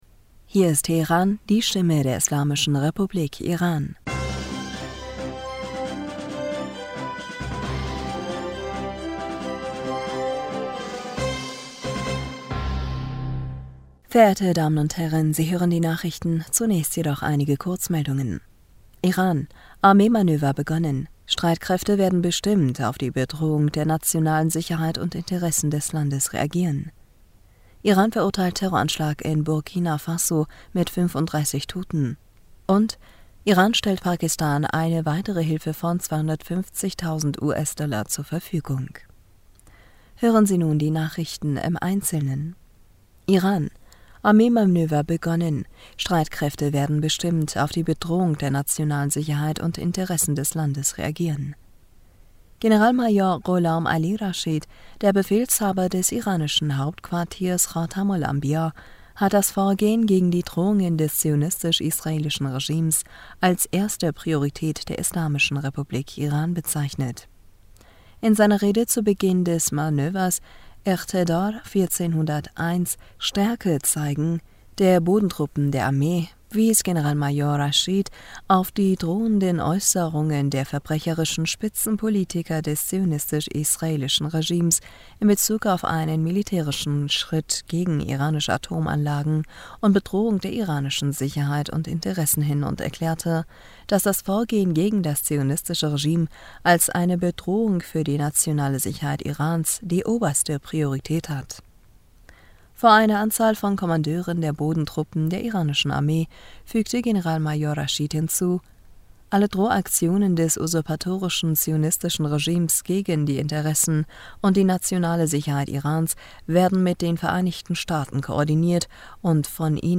Nachrichten vom 7. September 2022